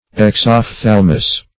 Search Result for " exophthalmus" : The Collaborative International Dictionary of English v.0.48: Exophthalmos \Ex`oph*thal"mos\, Exophthalmus \Ex`oph*thal"mus\, n. [NL.]